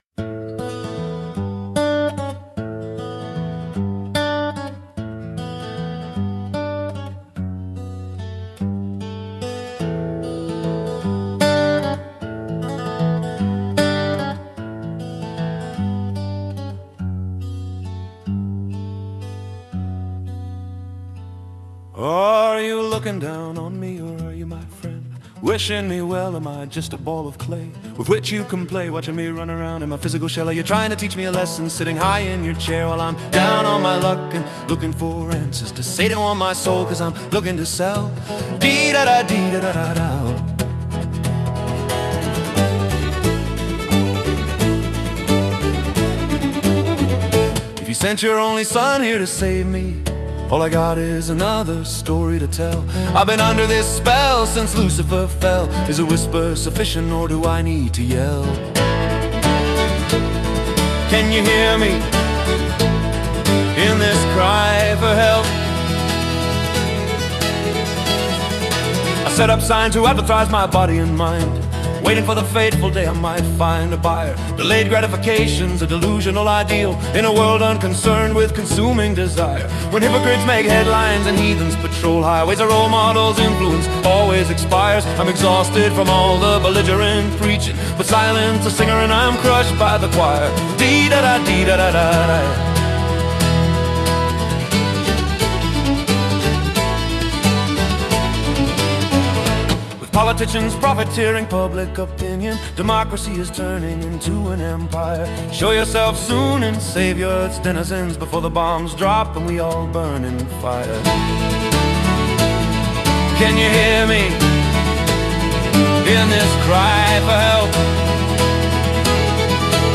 (Dylanesque social protest prayer)